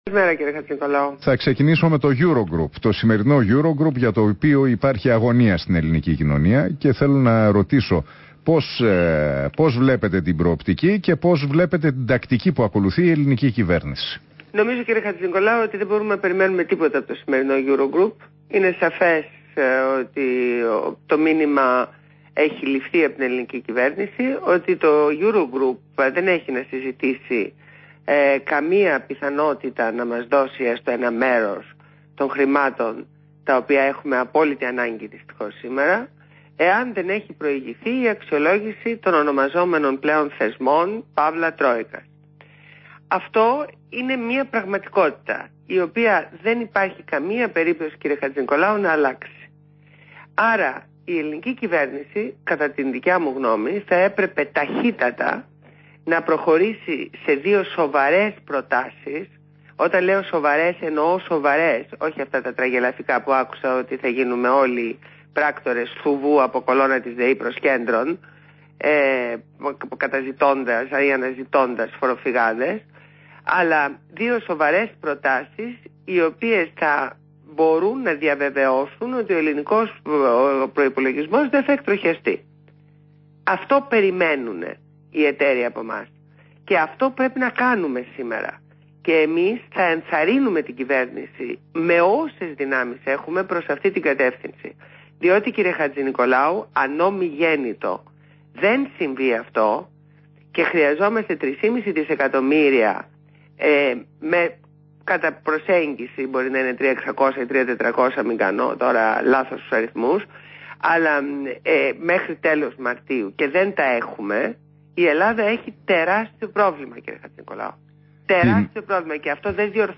Συνέντευξη στο ραδιόφωνο REALfm στον Ν. Χατζηνικολάου.